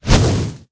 fireball4.ogg